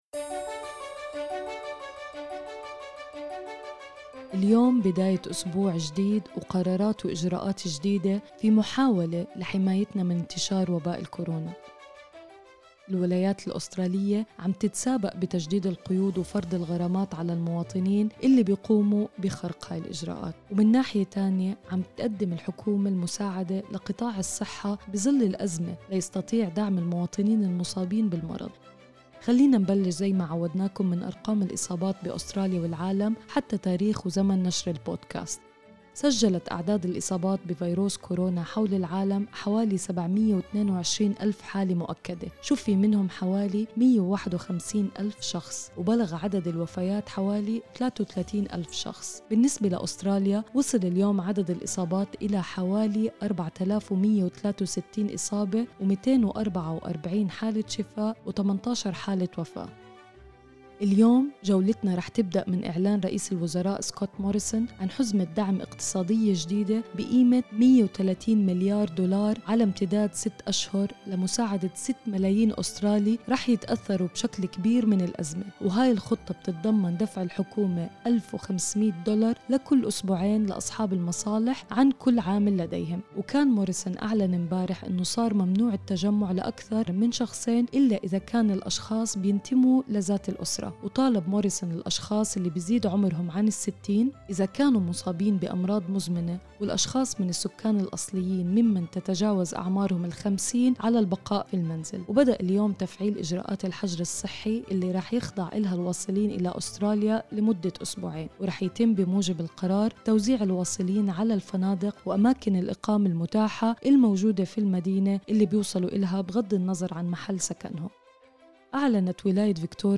corona_30_with_music_mixdown.mp3